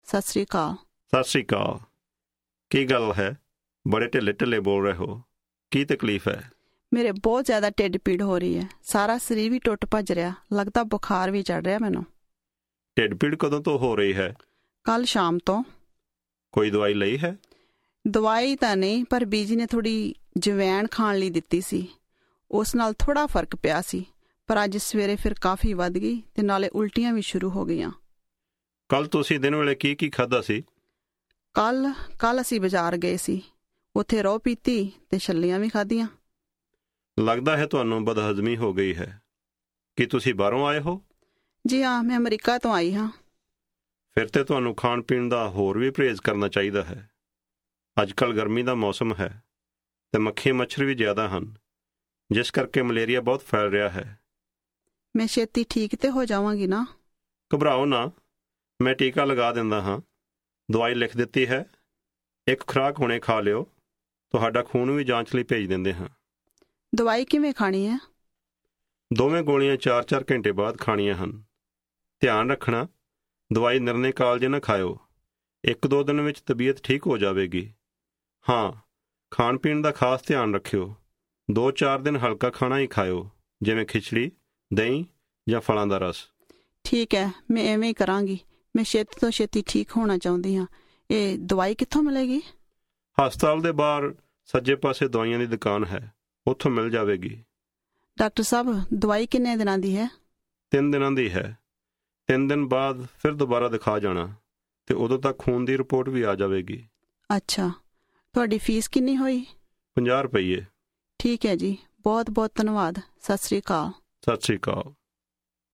Punjabi Conversation 12 Listen
doctor_and_patient_conversation.mp3